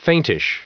Prononciation du mot faintish en anglais (fichier audio)
Prononciation du mot : faintish